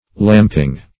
Lamping \Lamp"ing\